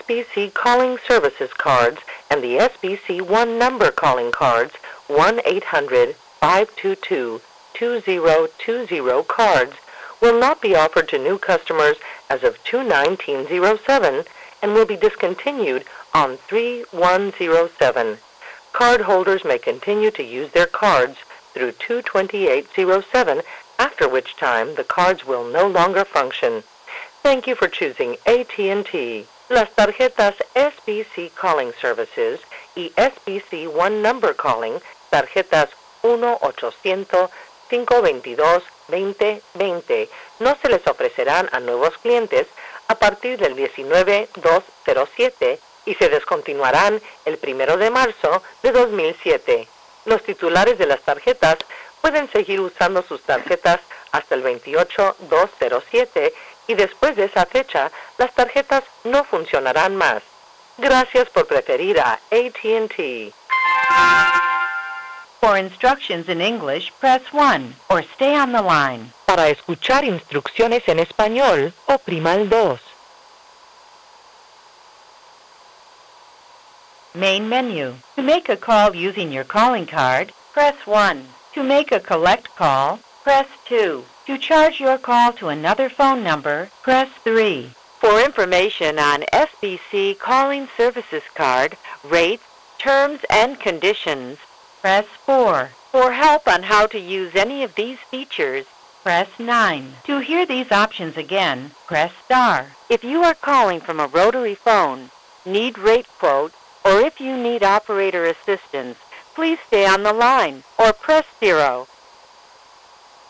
The following are examples of other local or long distance telephone company operator service platforms sounds and recordings.
SBC (AT&T Local) Calling Card Platform Prompts (February 2007)